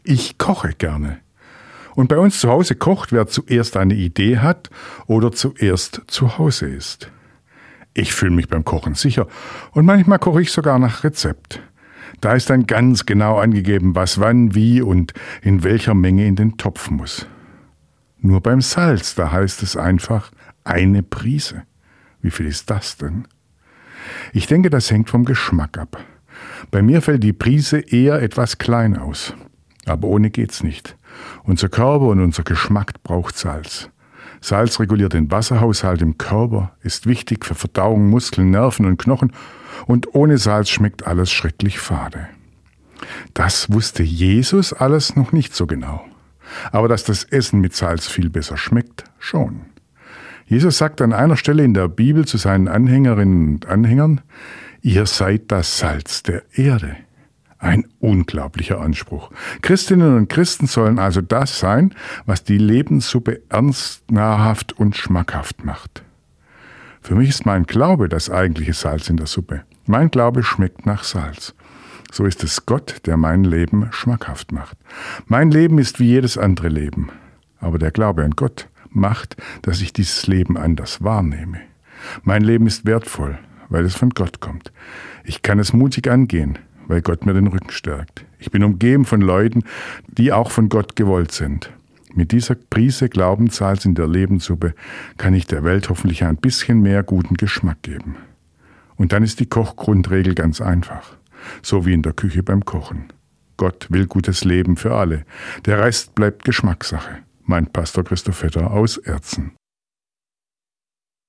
Radioandacht vom 13. August